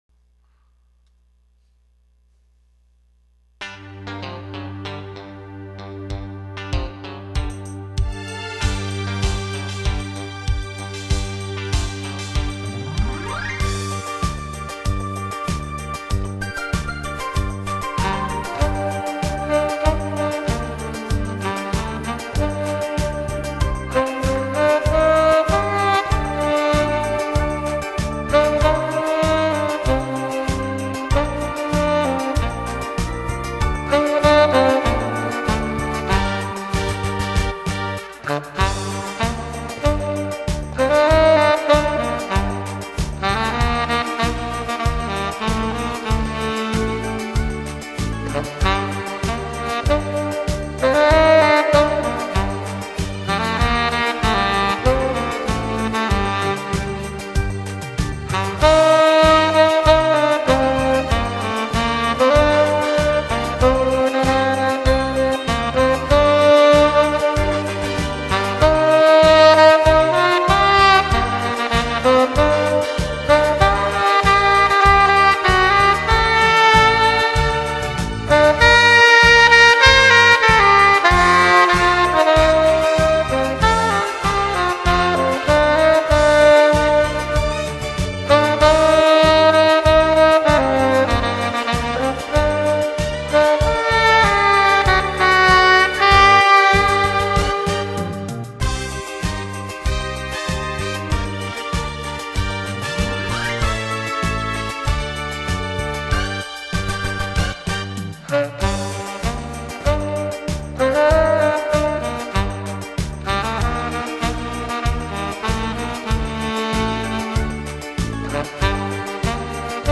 연주
연습도중 녹음